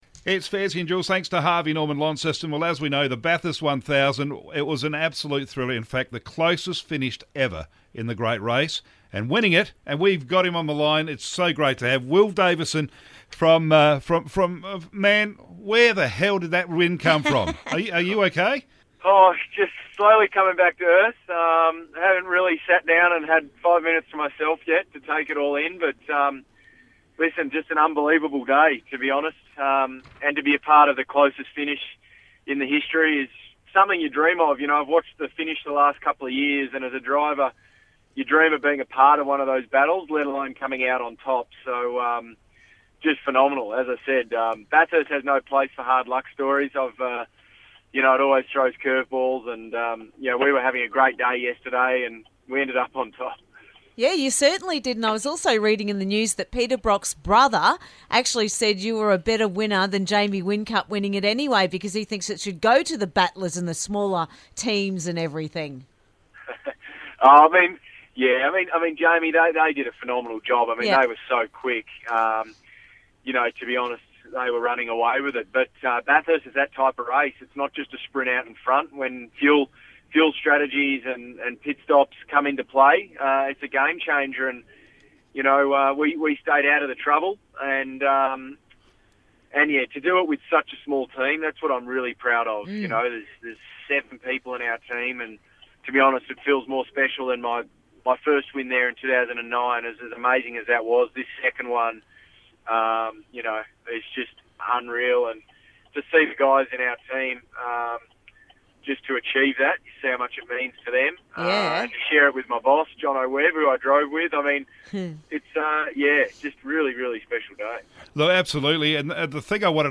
Interview - Bathurst Winner Will Davison